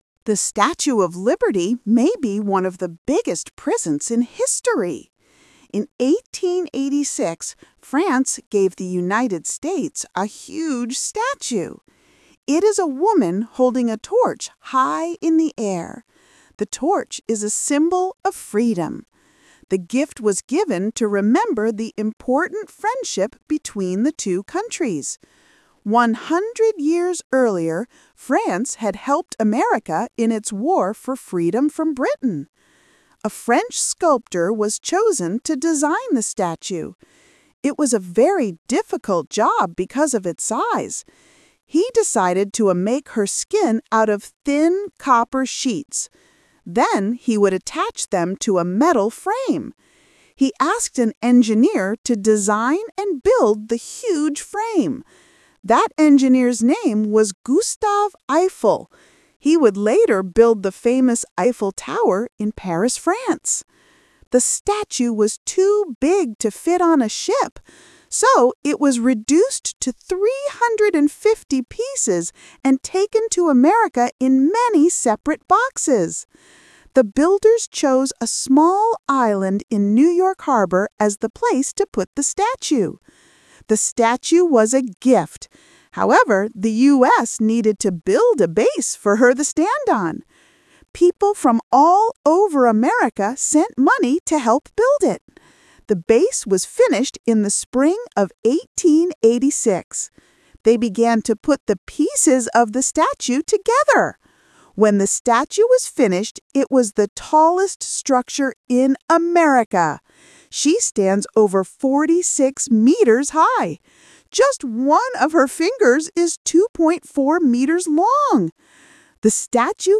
Talk/Lecture 1: You will hear a man talking about the Statue of Liberty.